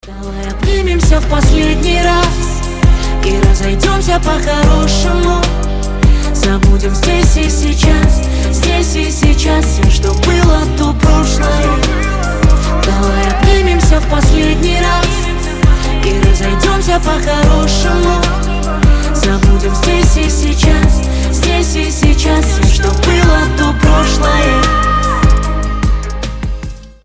• Качество: 320, Stereo
грустные
дуэт